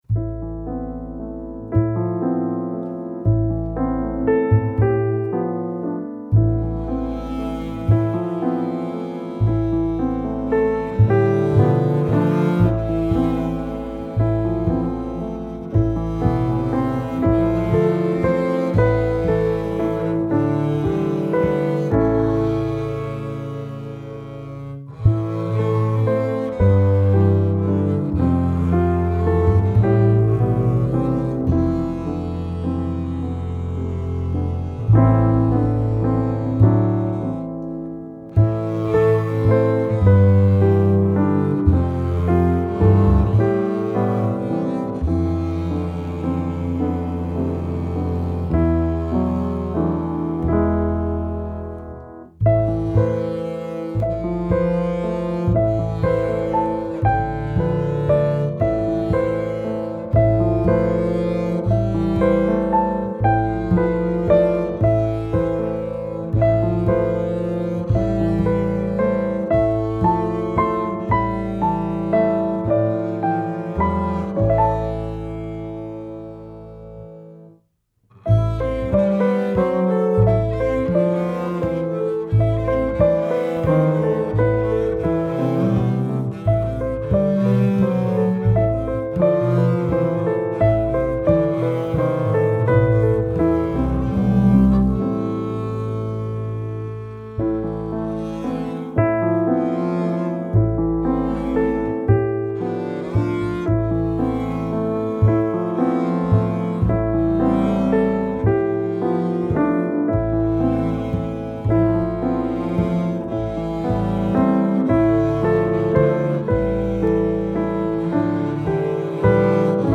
pianiste de jazz